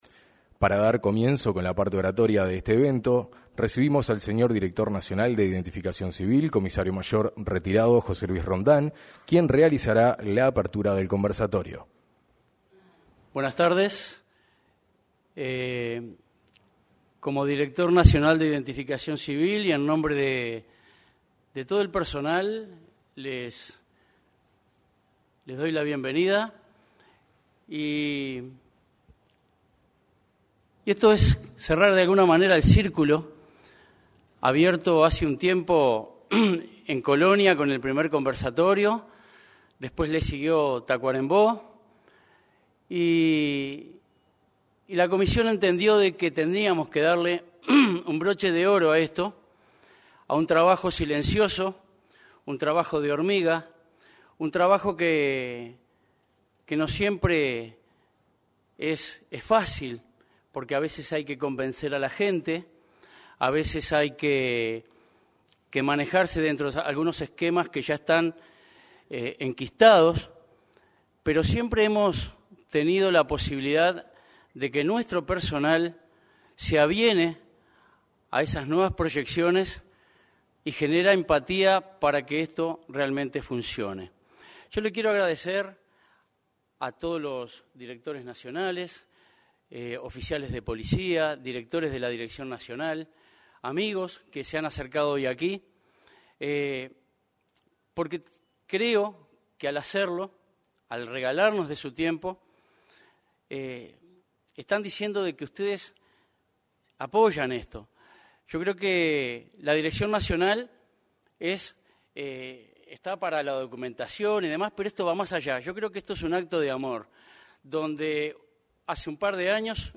Declaraciones del director nacional de Identificación Civil, José Luis Rondán